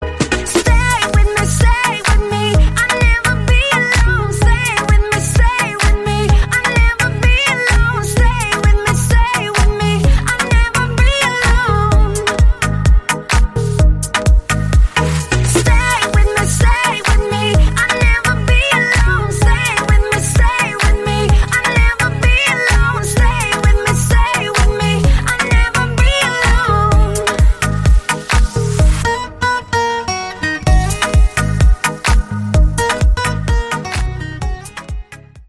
• Качество: 128, Stereo
гитара
мелодичные
Euro House